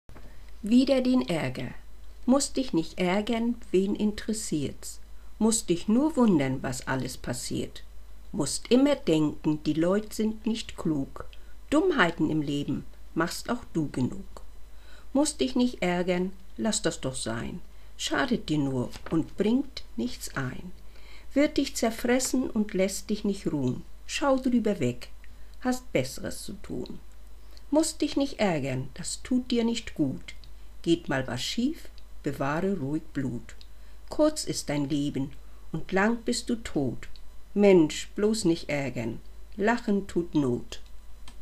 Hören Sie mal!  gesprochen